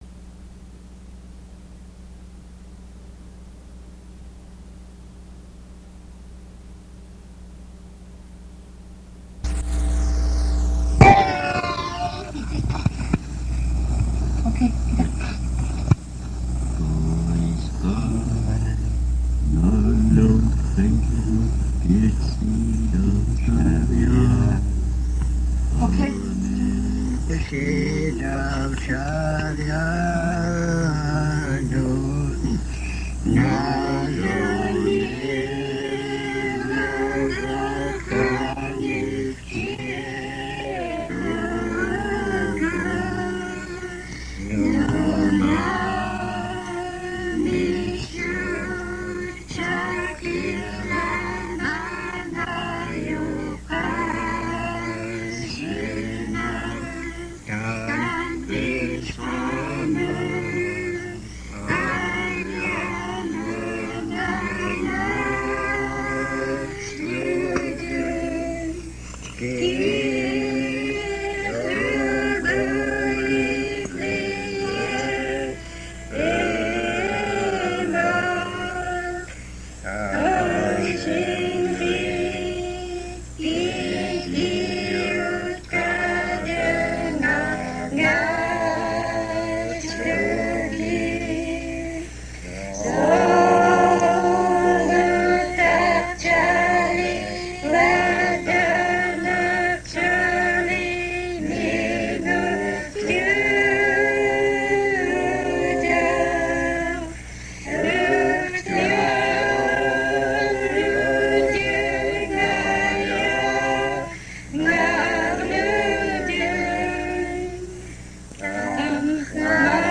and two women sing Russian Orthodox Church songs and Alutiiq songs. It was recorded in Karluk, Alitak, Akhiok, Kaguyak, and Old Harbor. Quality: poor, staticky.
Russian Orthodox Church singing